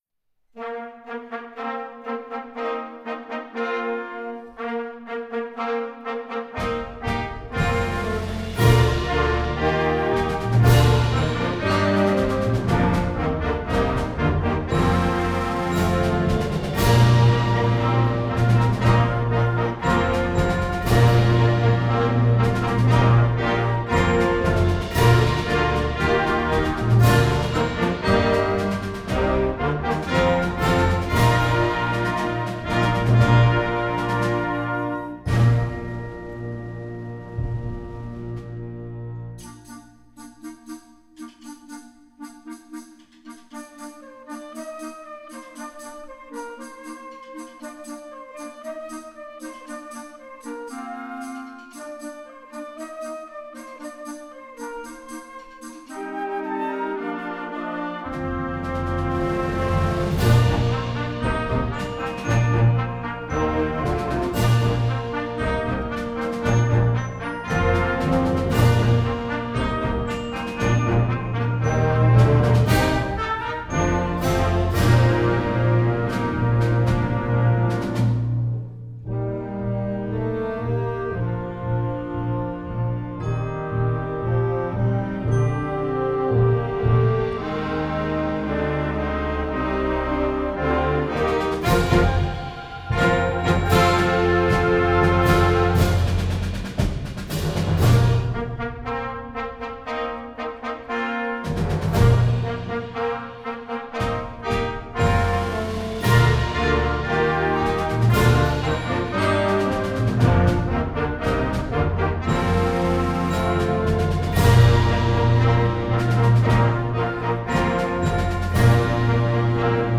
Gattung: Weihnachtslied für Jugendblasorchester
Besetzung: Blasorchester